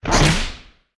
target_launch.ogg